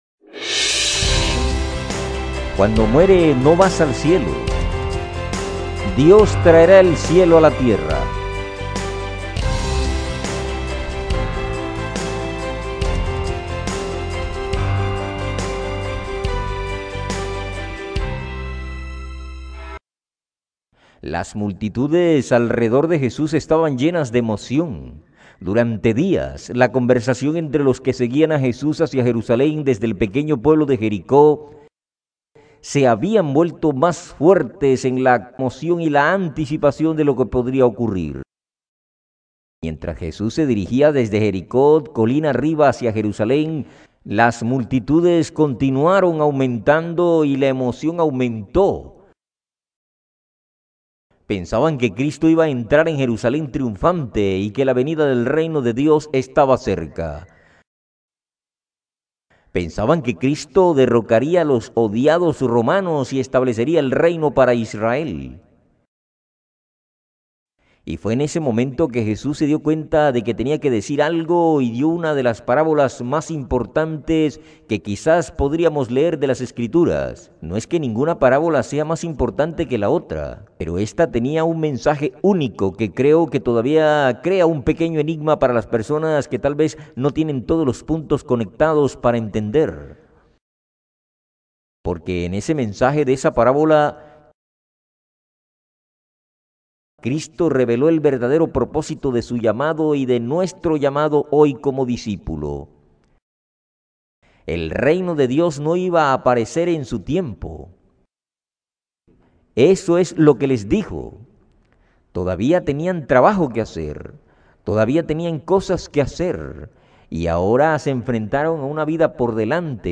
programa de televisión